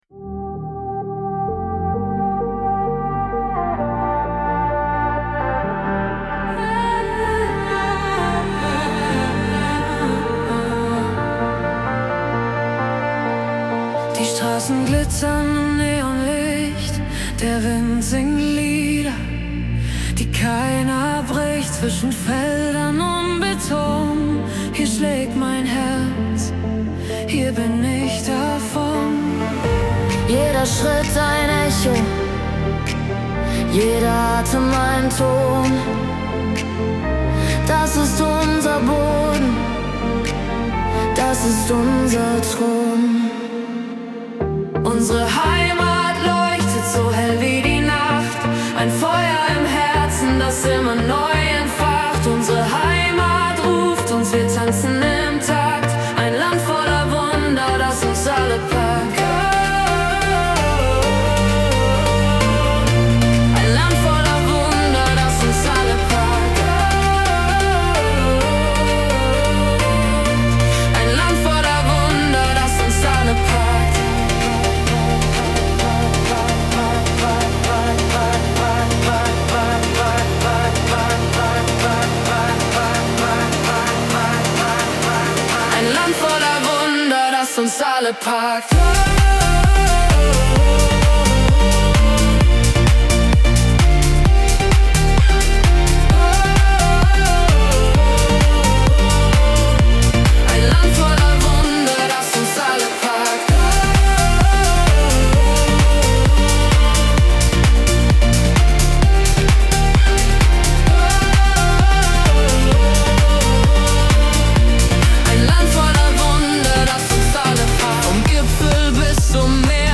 Ein Dance-Song, der verbindet